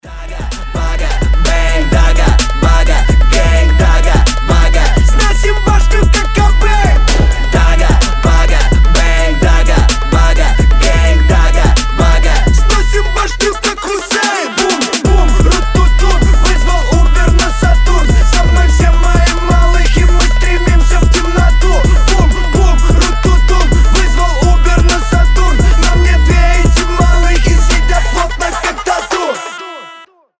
• Качество: 320, Stereo
громкие
восточные